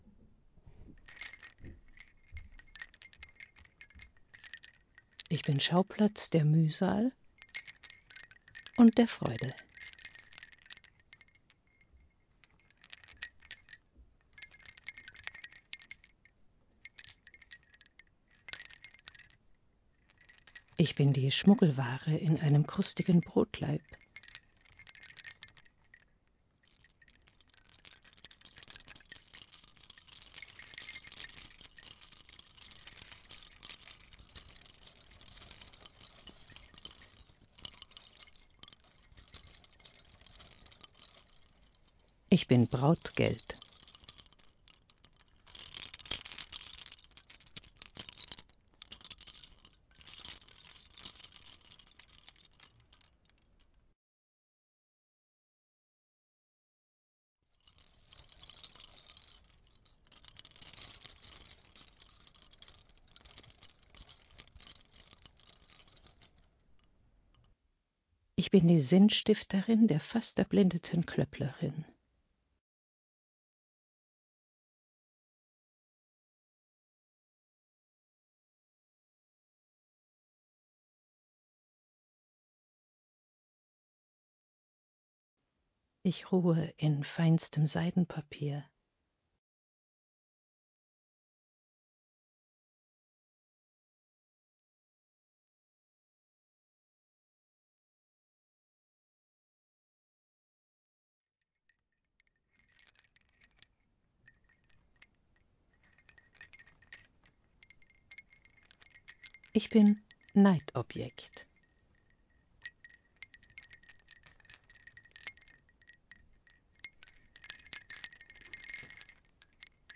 Klang-Installation aus Lautsprecherkabeln eines historischen Spitzenkragens, 2022/23
Aus den Lautsprechern ist ein Soundgeflecht zu hören, das sich aus leisen Klöppelgeräuschen sowie versprachlichten Texten zusammensetzt.